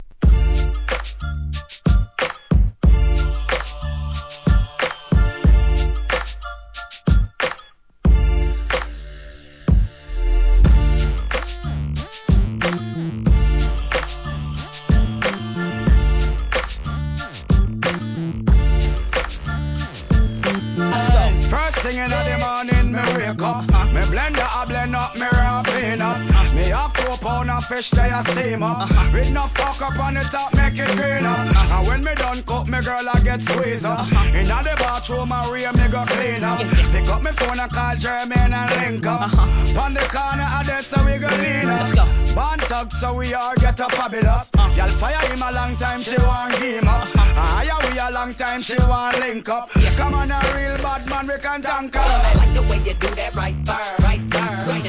REGGAE X HIP HOPブレンドMUSH UPシリーズ!!